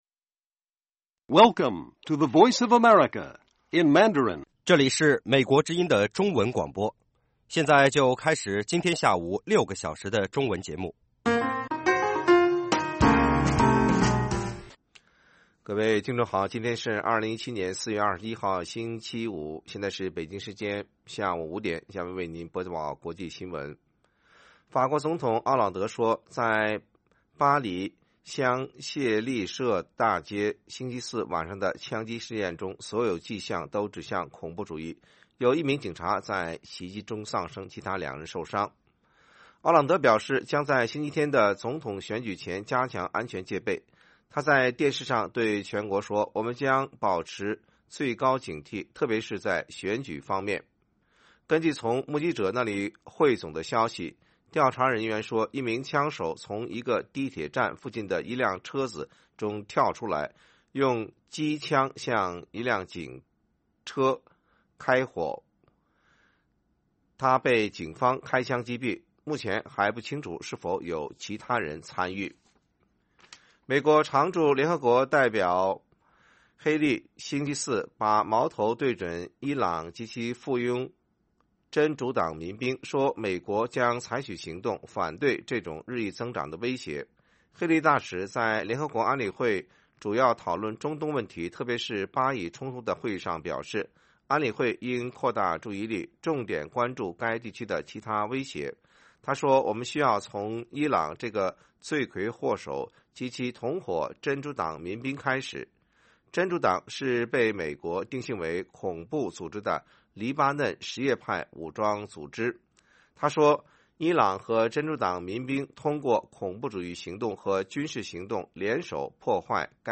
北京时间下午5-6点广播节目。